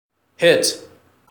Hit.wav